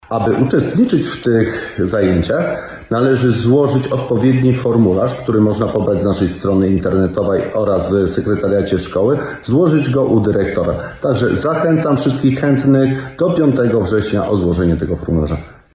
– Chodzi o dobre przygotowanie dzieci do sakramentu pierwszej Komunii Świętej oraz bierzmowania – wyjaśnia wójt Mirosław Cichorz.